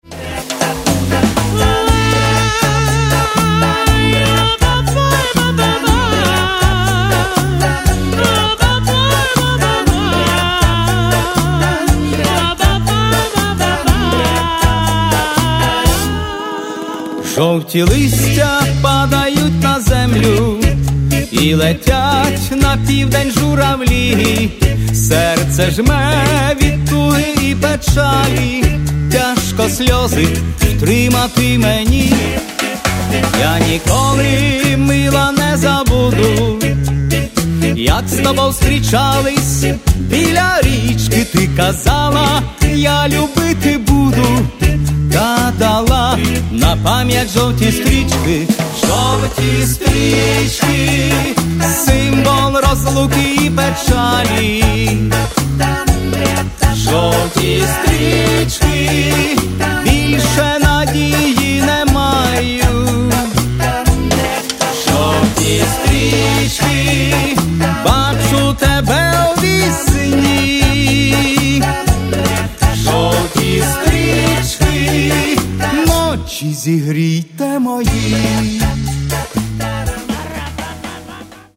Каталог -> Другое -> Вокальные коллективы